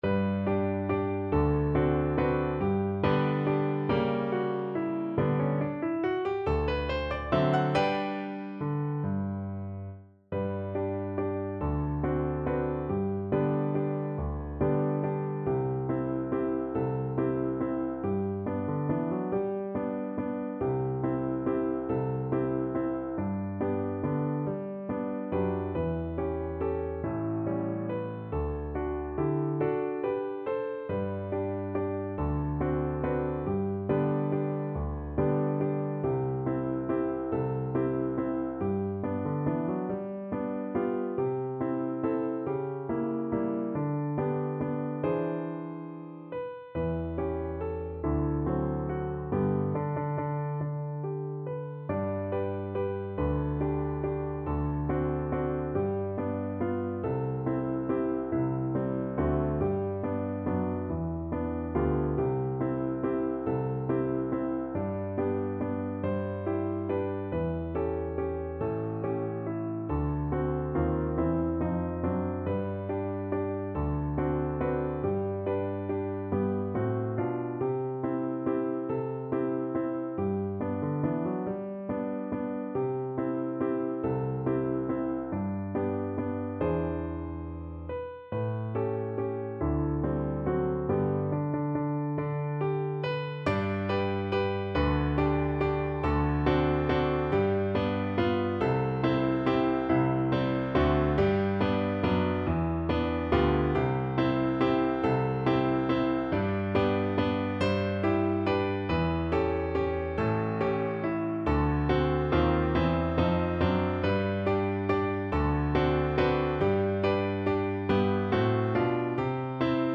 ~ = 140 Tempo di Valse
Pop (View more Pop Voice Music)